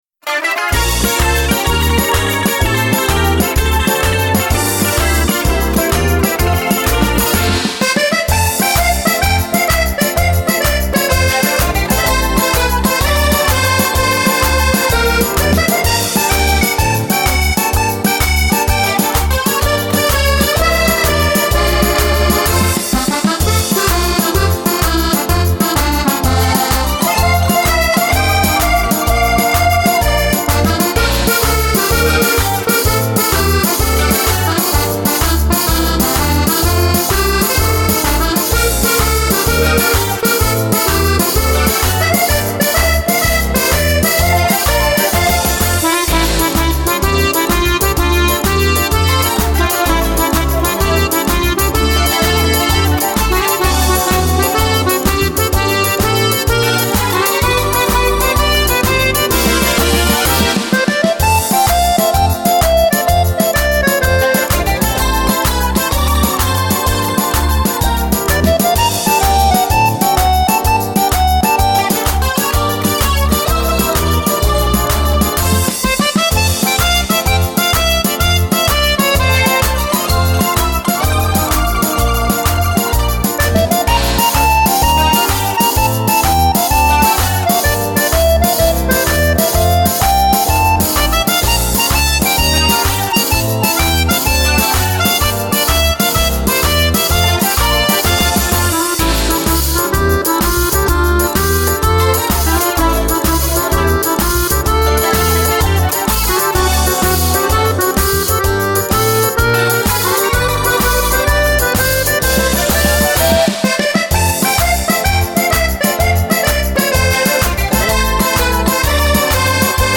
version accordéon intégrale